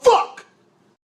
fuck sound effect meme
fuck-sound-effect-meme